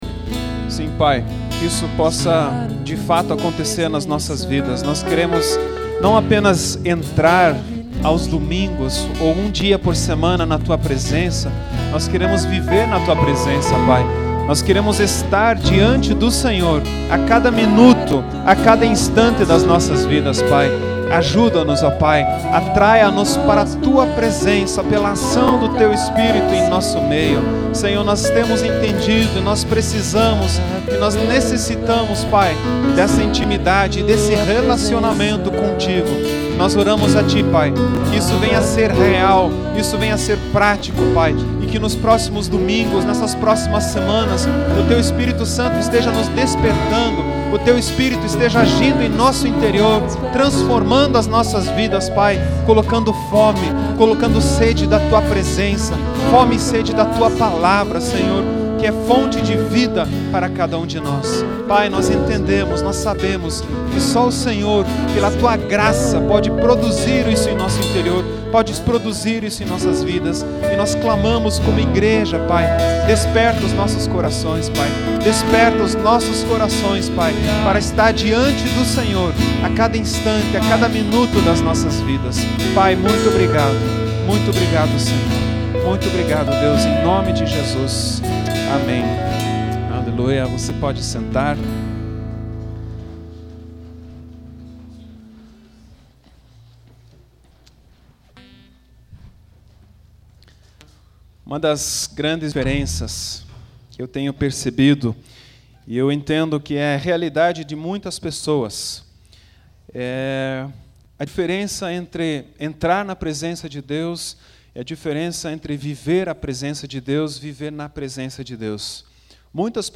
Ouça a mensagem que faz parte da temporada AMISTAD!